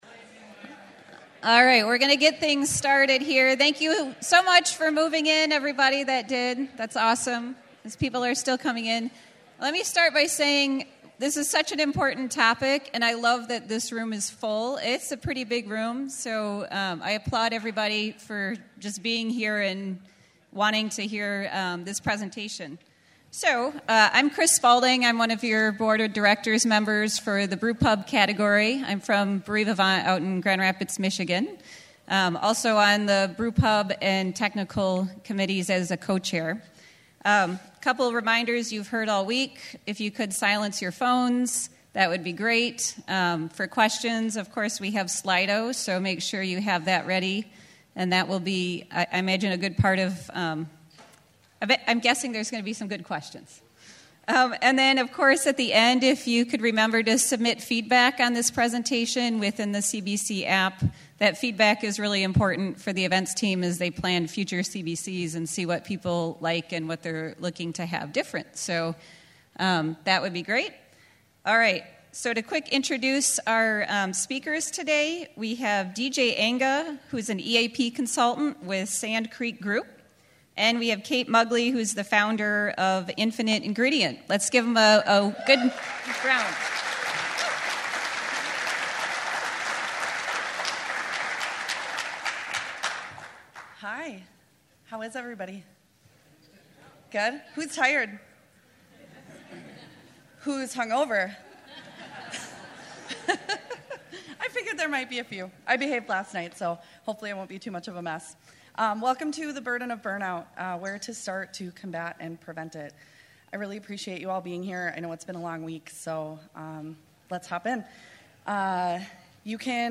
Seminar The Burden of Burnout: Where to Start to Combat and Prevent It Craft Brewers Conference 2022 Minneapolis, Minnesota